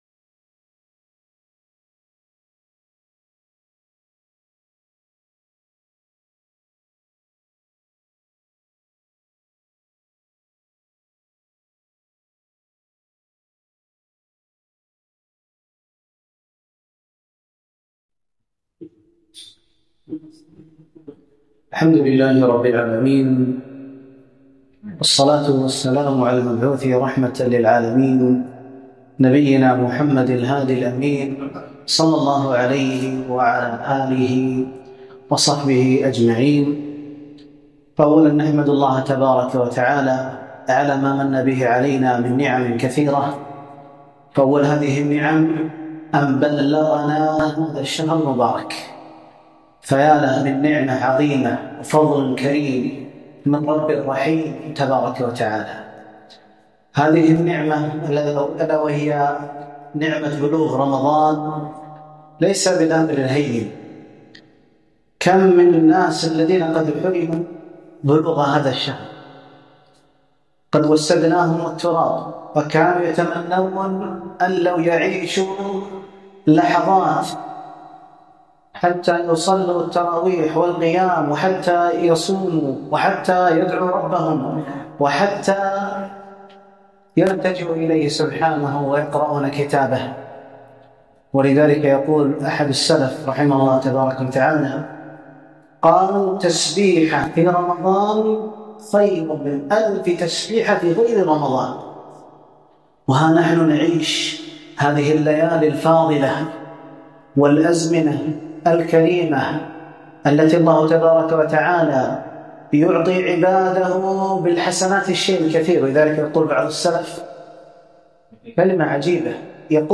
كلمة - يا باغي الخير أقبل